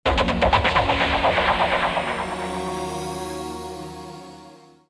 clickwave.mp3